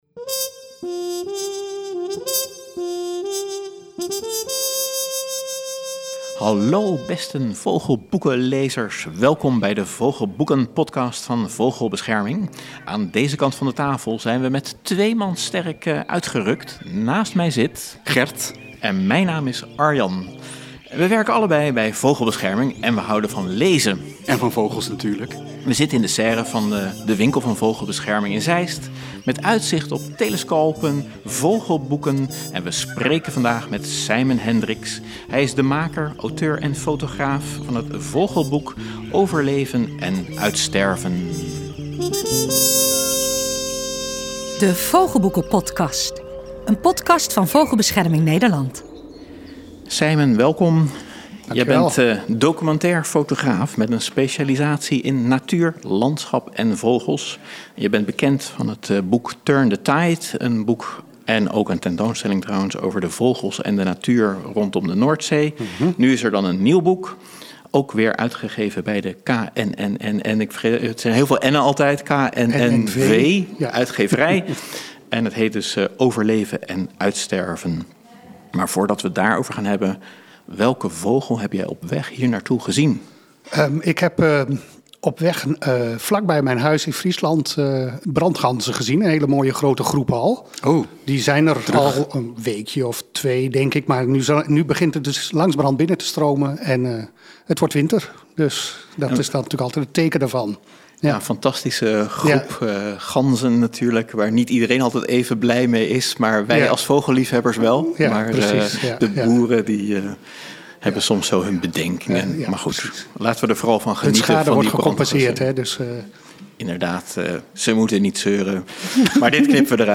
De makers van de Vogelboekenpodkast Redactie en interviews